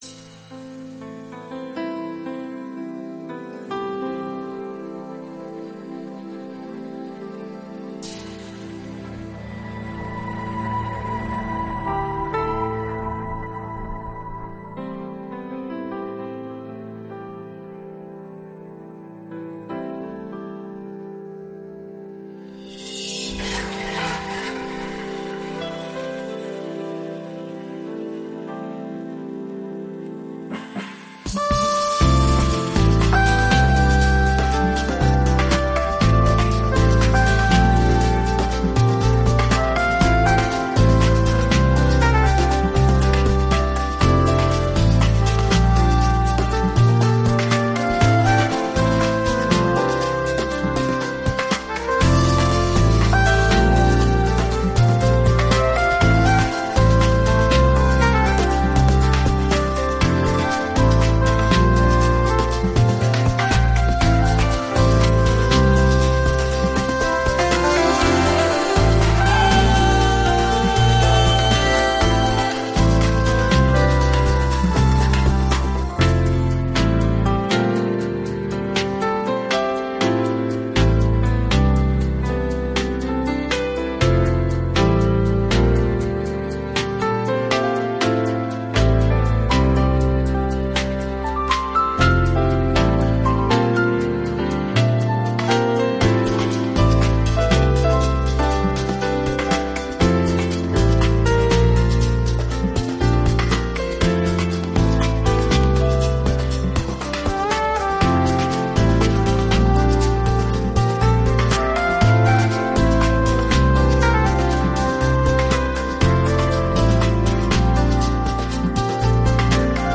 ・メインセクションは、ピアノとサックスが中心となり、リラックスしたビートが曲の進行を支えます。
・ブリッジは、テンポを少し落とし、サックスとピアノのソロが中心となる穏やかなパートを挿入します。
・アウトロは、再びスムースなテンポで、リラックスした雰囲気を保ちながらフェードアウトします。